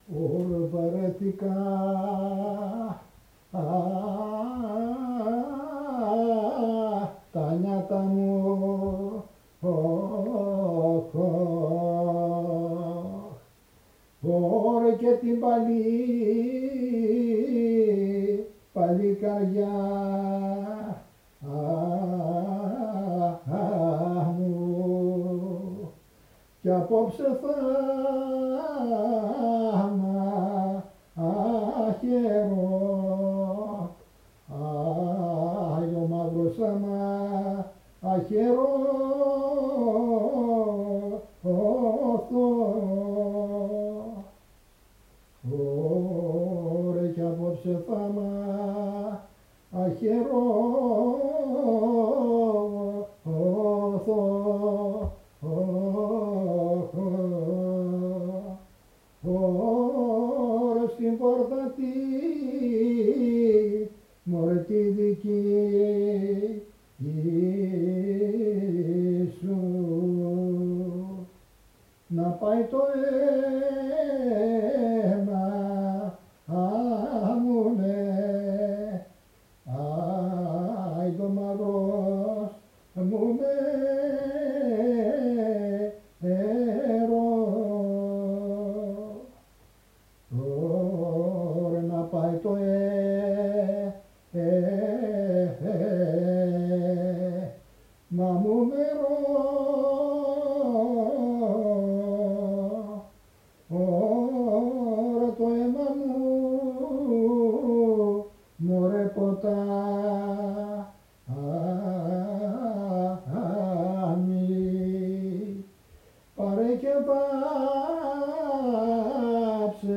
Επιτόπια έρευνα σε κοινότητες των Σαρακατσάνων της περιοχής Σερρών για τον εντοπισμό του τραγουδιστικού τους ρεπερτορίου